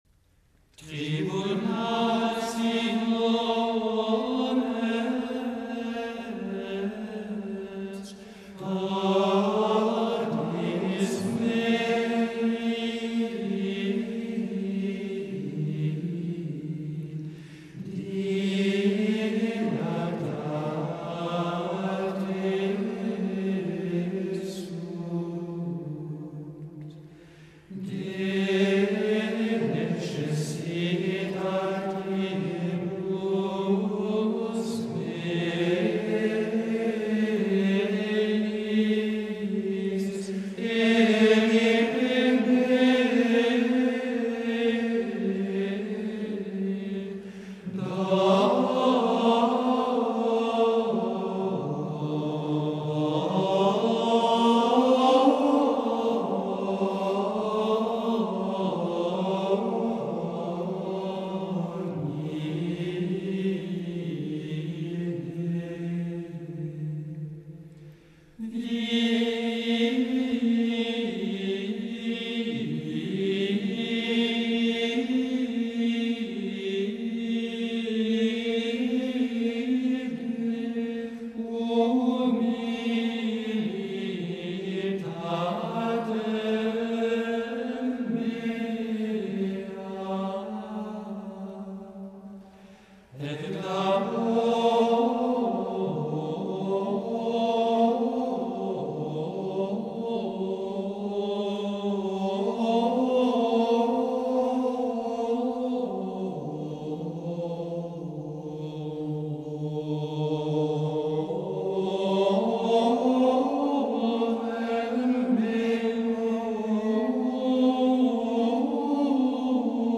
Il unit en effet un texte poignant et douloureux à une mélodie extrêmement joyeuse.
Elle est on ne peut plus classique en 5 mode : trois notes (Fa-La-Do) la propulsent vers la dominante du mode, en un arpège sans équivoque qui exprime la joie par sa luminosité même.